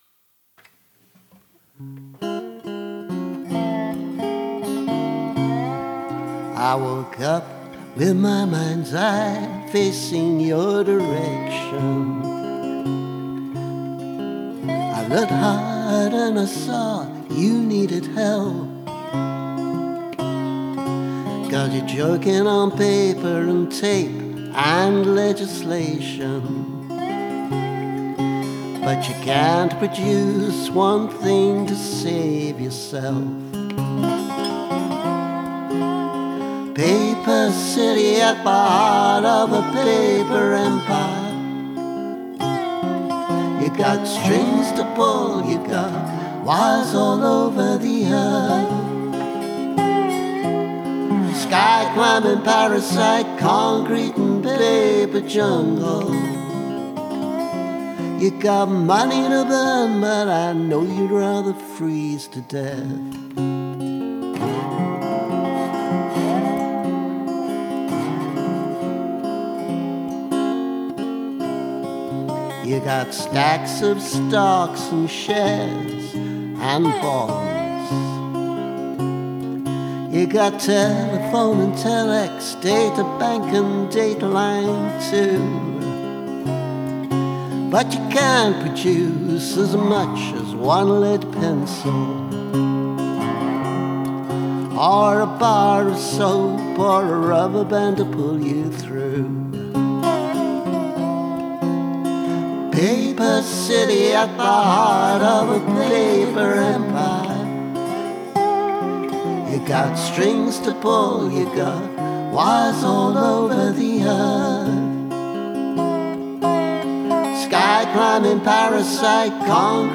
Rough mix
A cheerful rock ‘n’ roll-ish ditty about the breakdown of the global economy, written in the very early 80s. These days I wonder which will go first: the economy, or the globe.
paper-city-with-slide.mp3